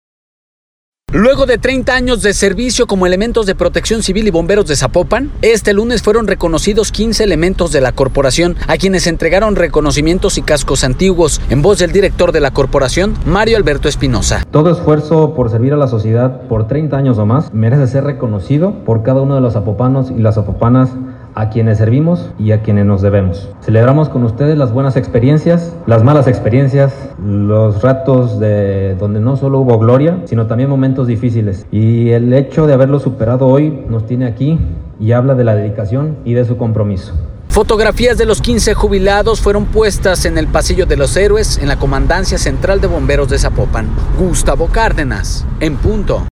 Luego de 30 años de servicio como elementos de Protección Civil y Bomberos de Zapopan, este lunes reconocieron a 15 elementos de la corporación, a quienes entregaron reconocimientos y cascos antiguos, en voz del director de la corporación, Mario Alberto Espinoza.